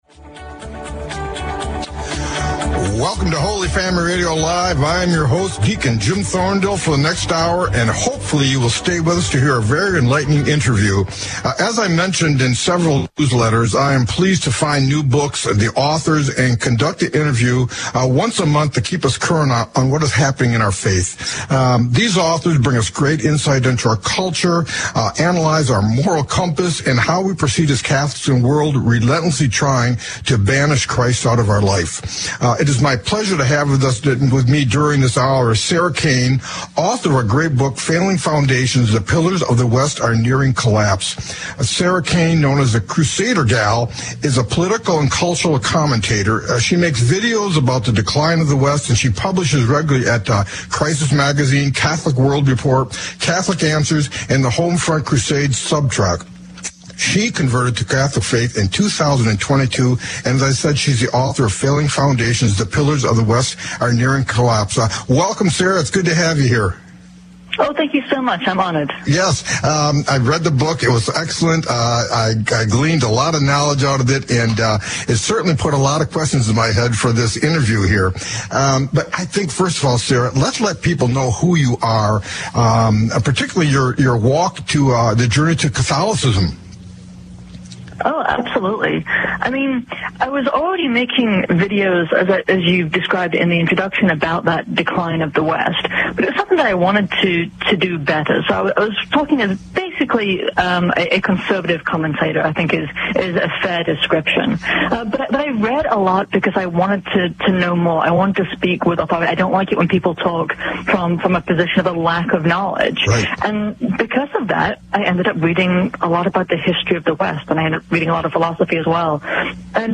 I was interviewed live